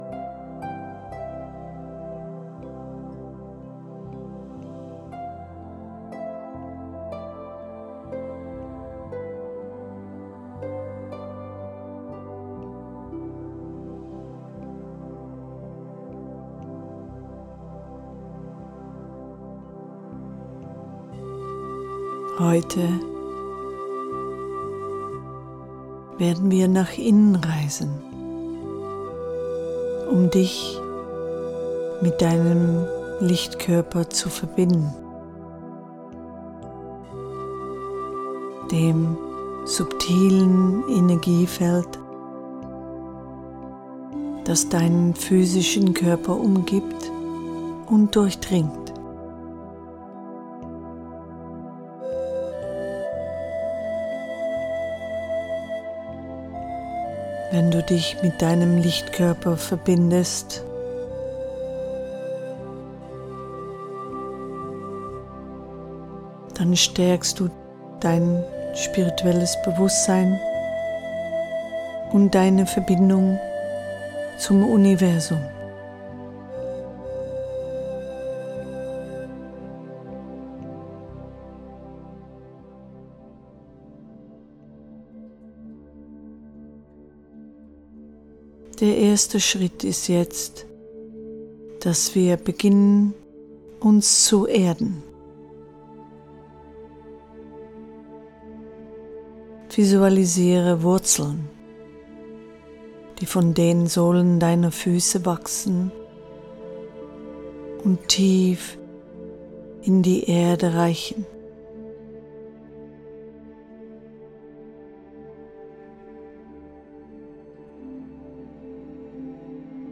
Geführte Meditation